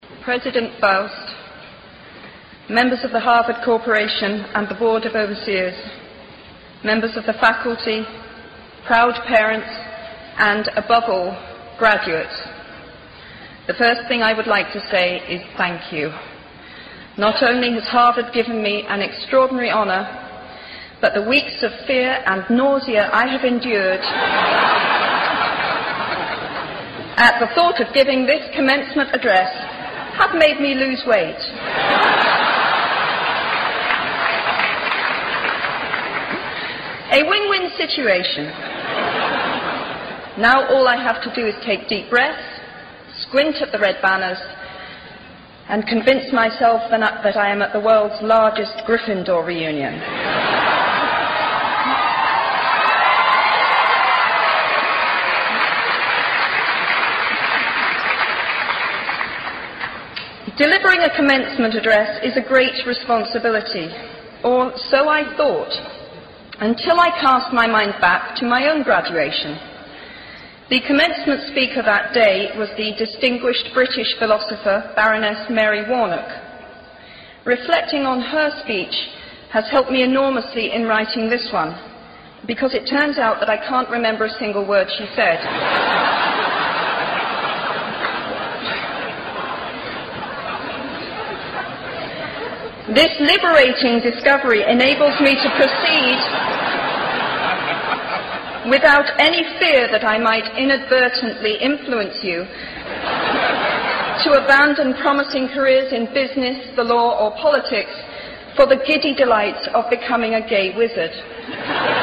偶像励志英语演讲19:失败的好处和想象的重要性(1) 听力文件下载—在线英语听力室
在线英语听力室偶像励志英语演讲19:失败的好处和想象的重要性(1)的听力文件下载,《偶像励志演讲》收录了娱乐圈明星们的励志演讲。